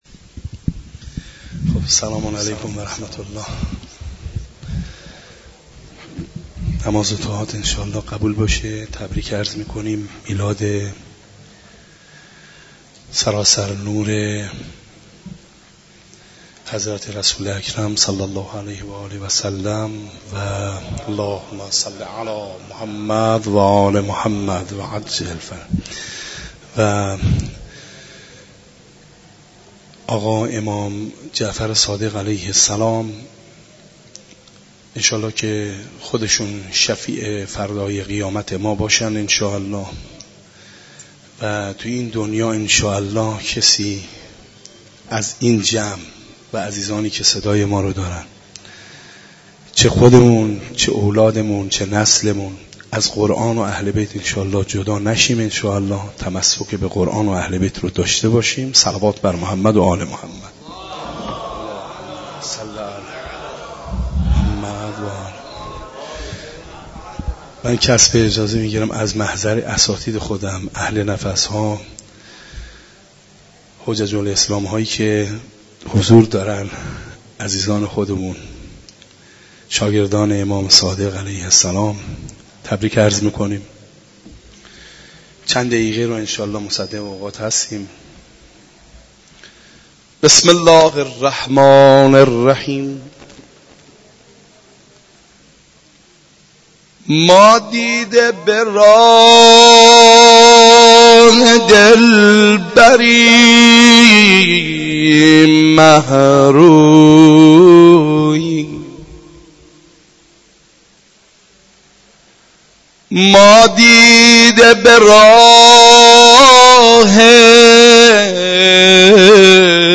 شادیانه عید خجسته ولادت پیامبر اکرم (ص) و امام جعفر صادق (ع) در مسجد دانشگاه کاشان برگزار گردید
مدیحه سرایی